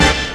68_11_stabhit-A.wav